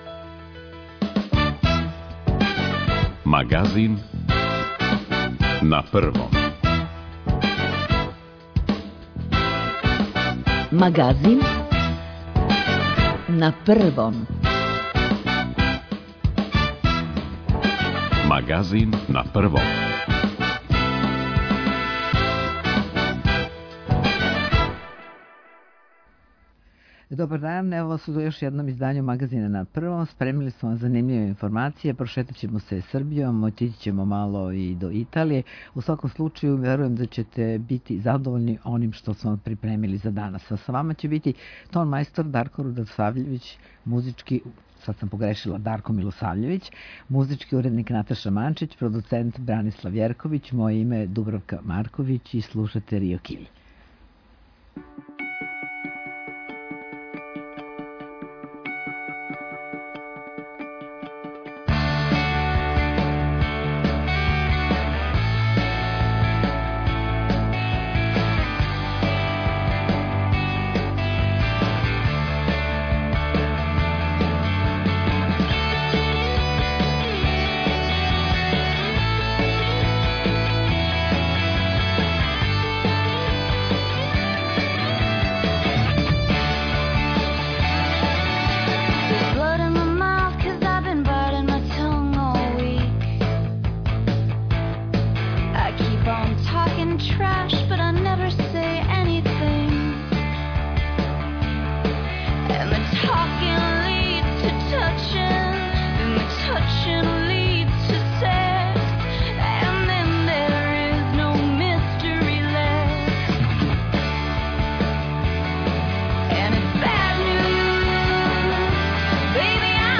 Доносимо најновије информације о епидемији која је захватила велики део света, тражимо савете стручњака о томе како се понашати у условима епидемије и ванредног стања, пратимо стање на терену, слушамо извештаје наших репортера из земље и света.